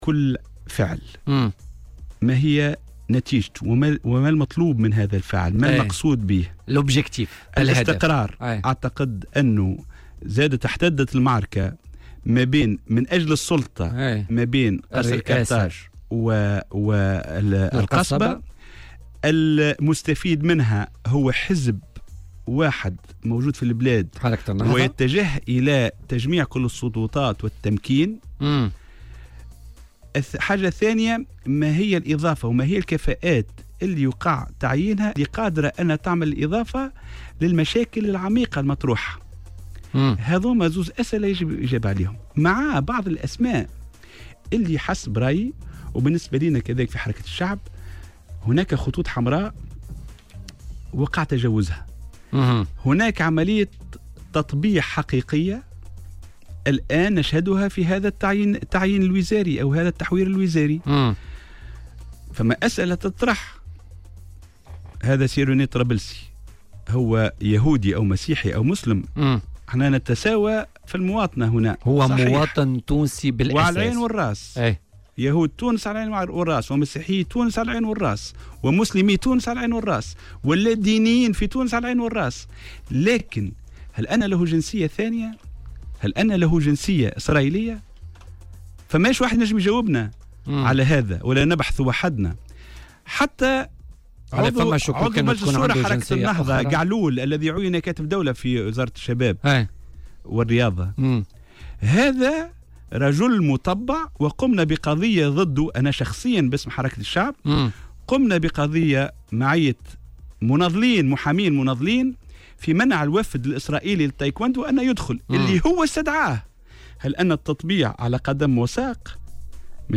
أكد عضو المكتب السياسي لحركة الشعب هيكل المكي ومحامي وزير أملاك الدولة والشؤون العقارية مبروك كورشيد ضف بولتيكا اليوم الثلاثاء أن احترازه على تعيين روني الطرابلسي وزير للسياحة ليس بوصفه يهوديا، لأن اليهود أشقاء للتونسيين.